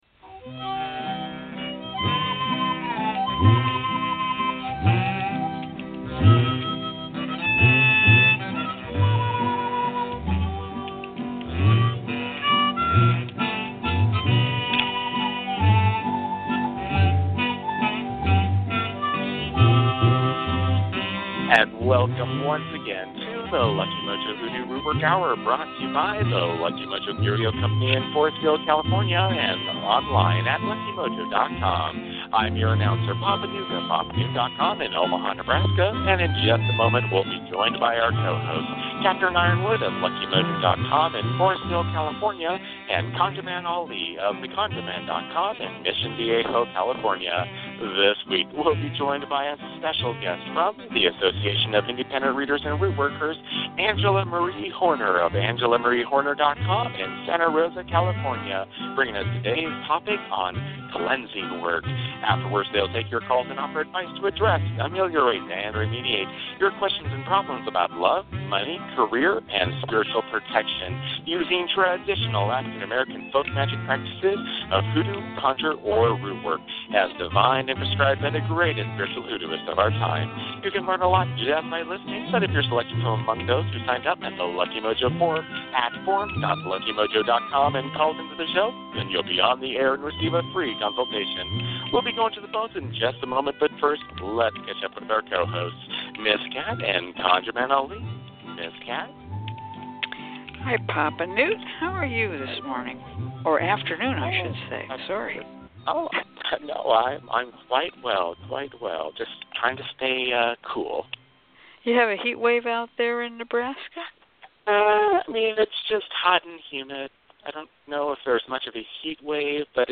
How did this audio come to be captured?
And we are LIVE and ON THE AIR!